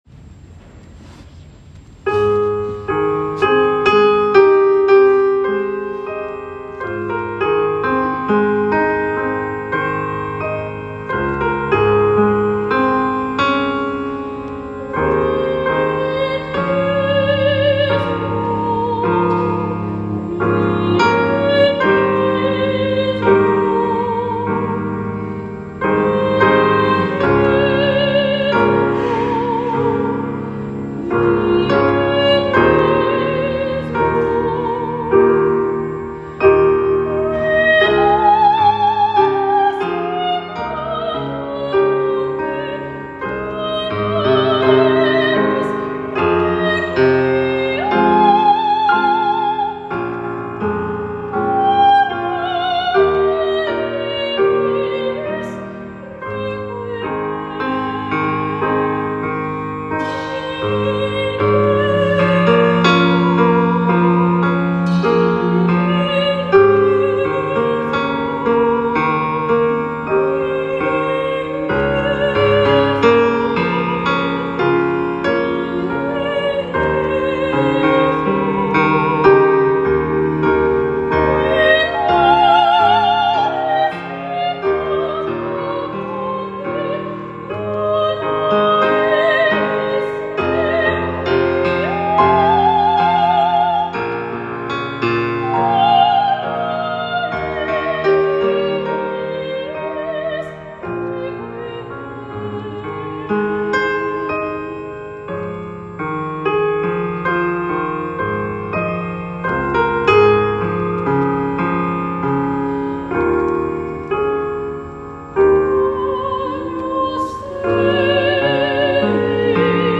Duo de canto, chelo y piano.